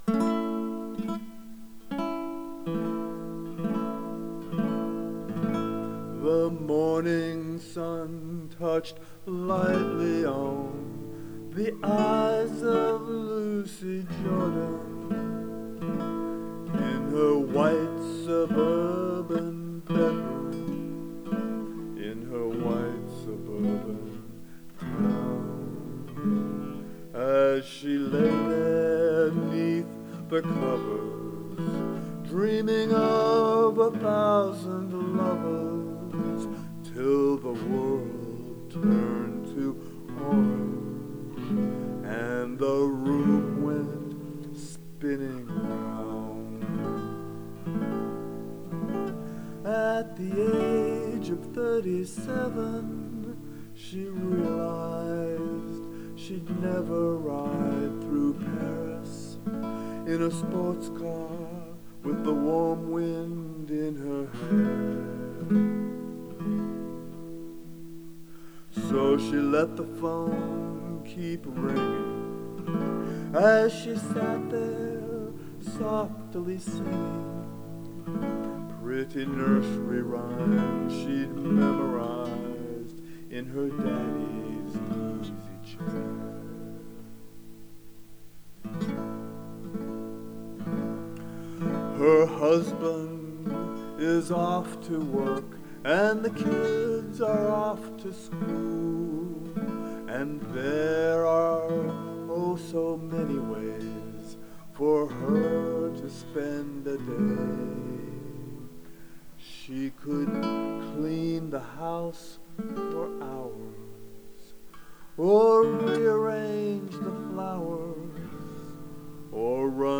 A slightly dreary version
Nylon string guitar, with my earnest attempt at vocals.